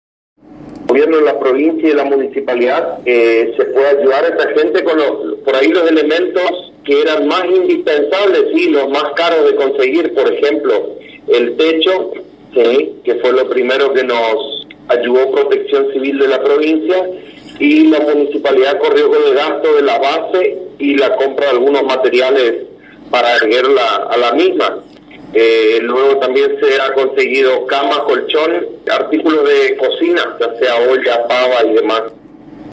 En una charla exclusiva con la ANG el subsecretario de gobierno Del Rivero Edgardo, contó lo sucedido con este vecino que perdió todos sus bienes después de un terrible incendio.